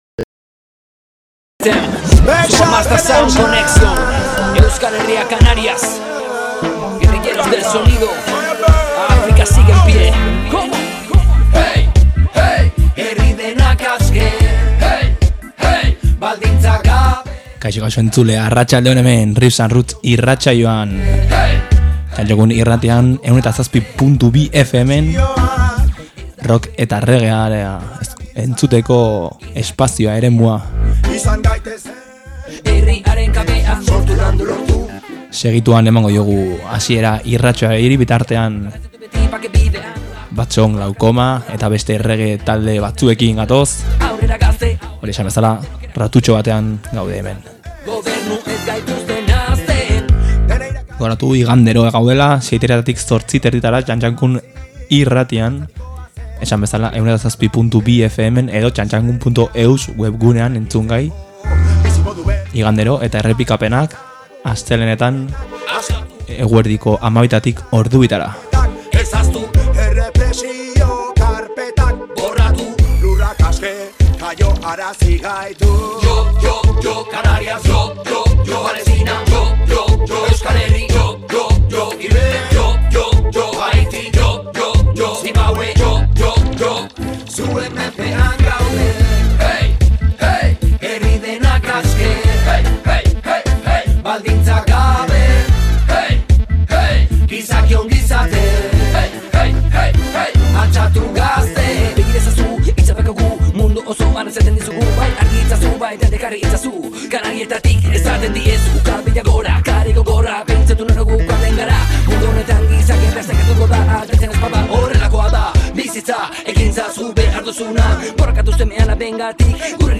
Soilik reggae-z beteriko irratsaioa. Reggae roots binilo batzuk bueltak ematen jarri eta garai berrien abestiak entzuteko saioa.